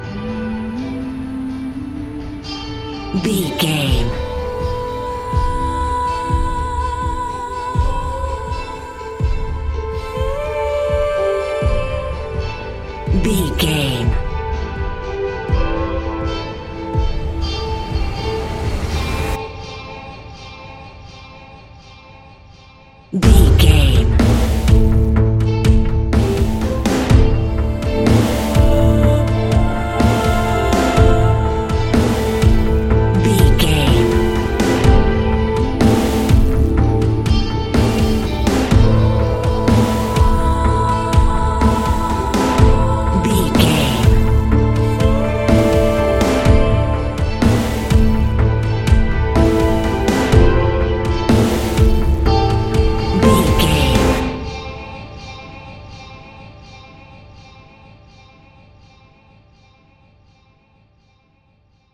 Ionian/Major
D
electronic
techno
trance
synths
synthwave